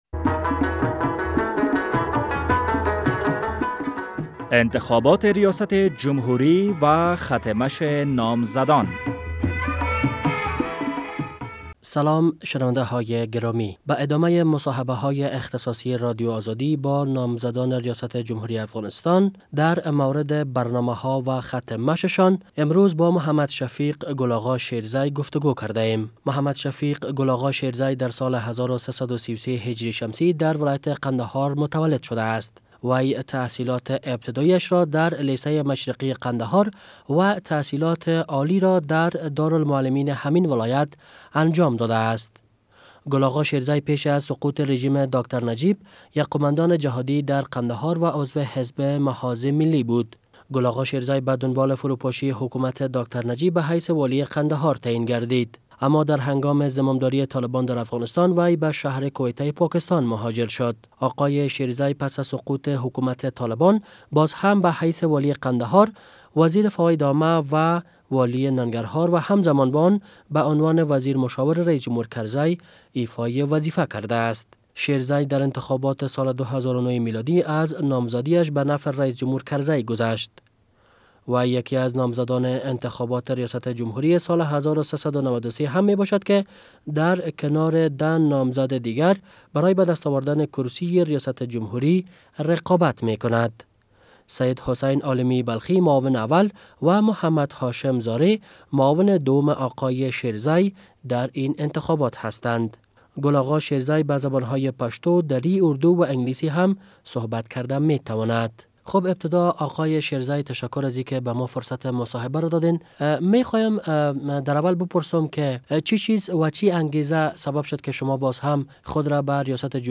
مصاحبهء اختصاصی با محمد شفیق گل آغا شیرزی